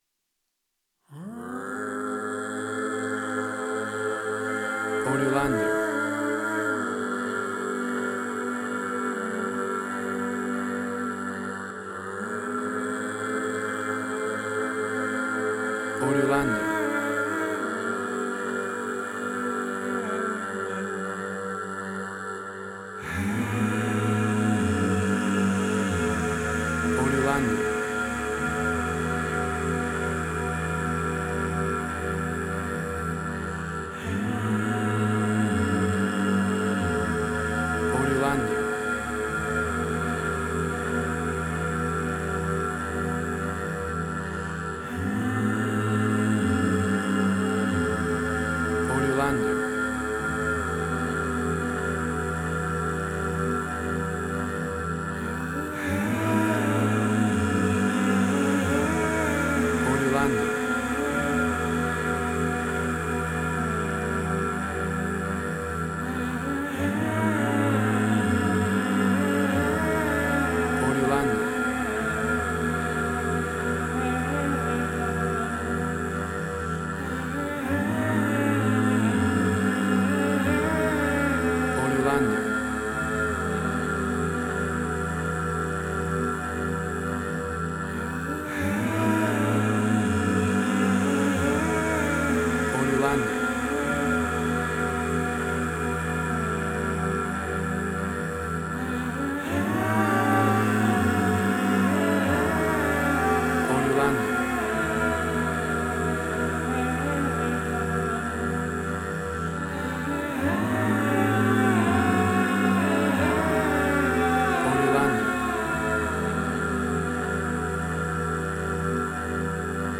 WAV Sample Rate: 24-Bit stereo, 44.1 kHz